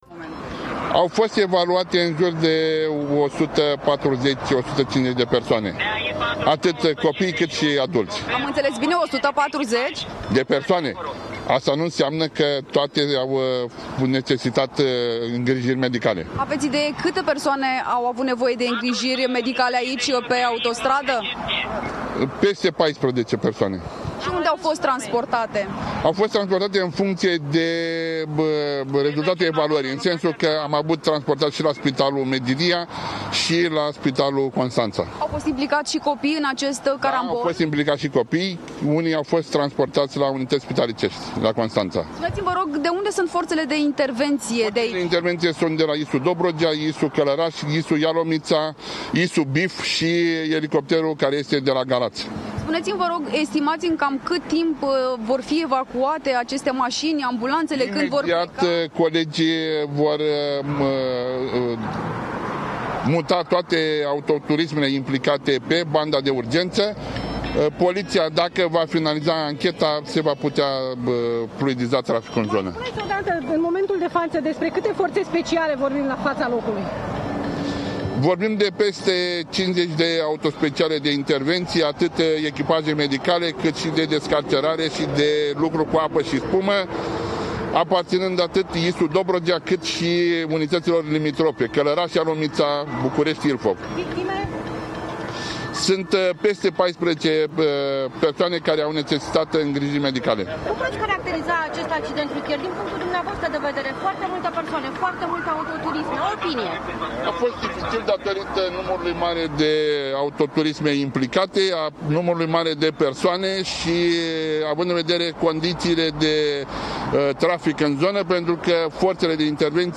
• Mihai-Cristian Amarandei, șeful ISU Dobrogea